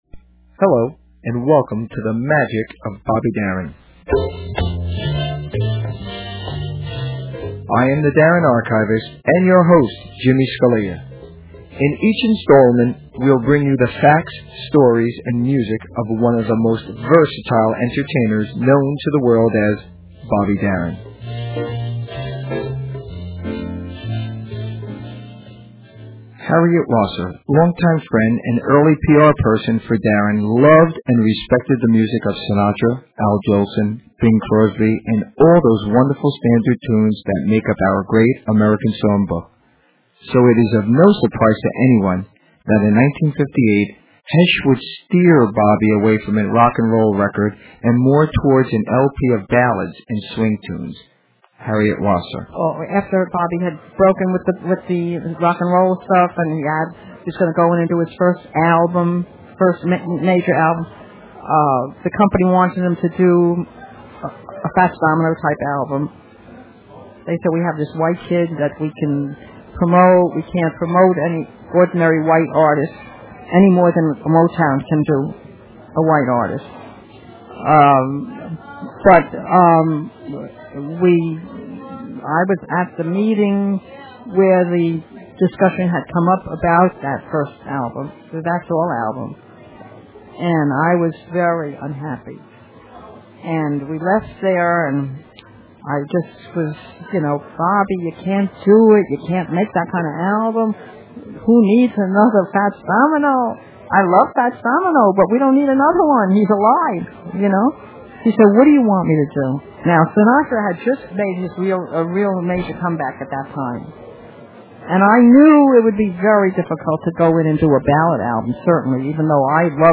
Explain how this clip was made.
Technical Note: Please keep in mind that due to time and space contraint on the internet, and legal worries of sharing too much, the music portions have been edited and the quality is subpar.